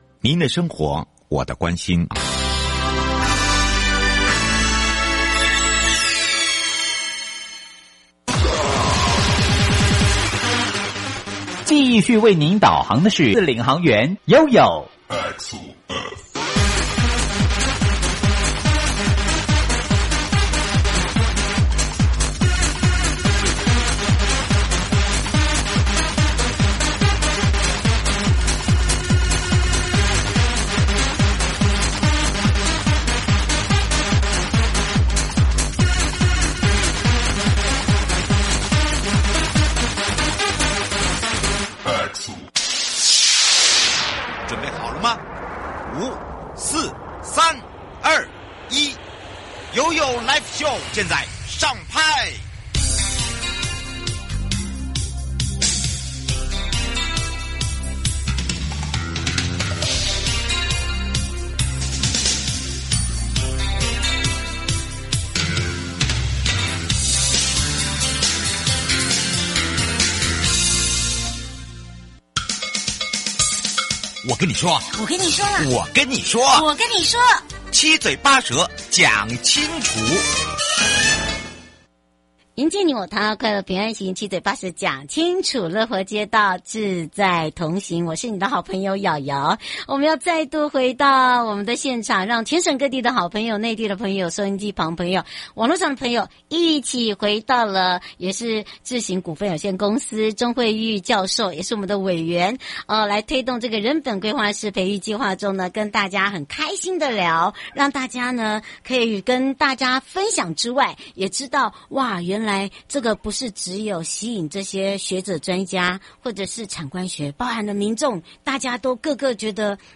受訪者： 營建你我他 快樂平安行~七嘴八舌講清楚~樂活街道自在同行!